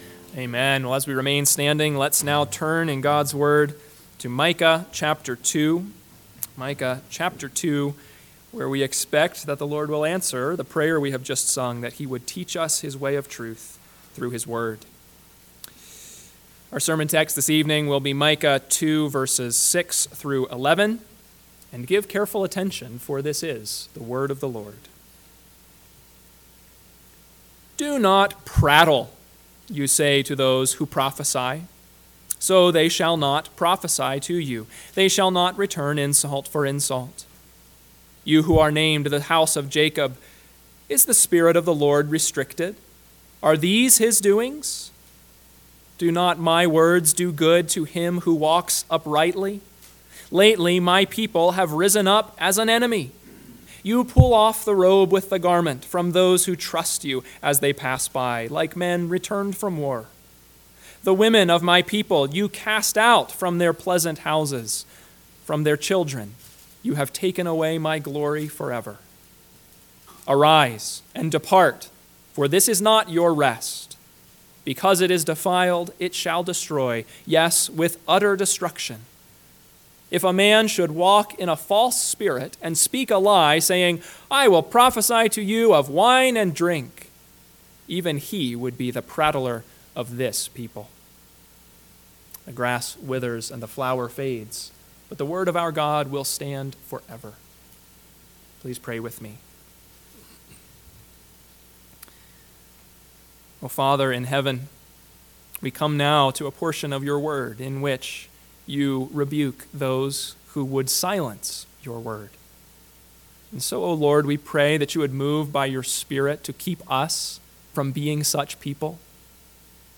PM Sermon – 9/1/2024 – Micah 2:6-11 – Northwoods Sermons